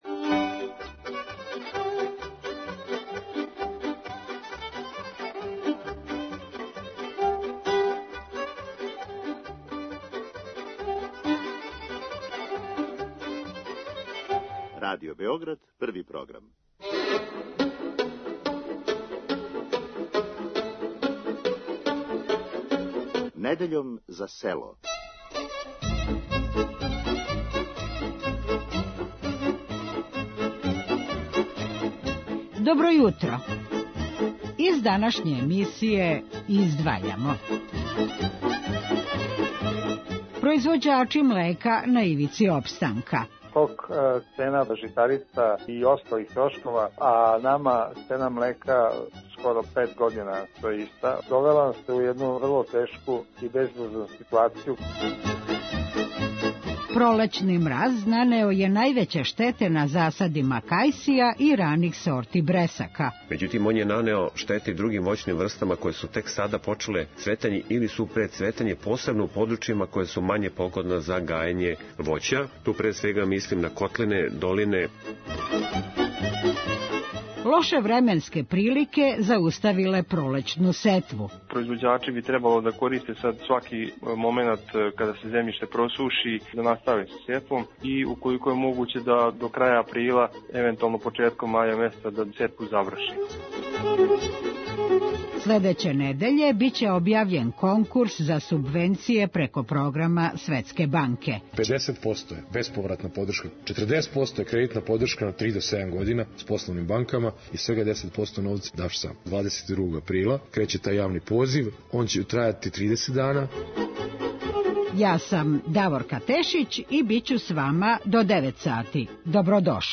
О тешком положају у коме су се нашли произвођачи су разговарали са министром пољопривреде Браниславом Недимовићем који је истакао да ће држава спречити сваки покушај злоупотребе у вези са квалитетом млека при увозу у нашу земљу.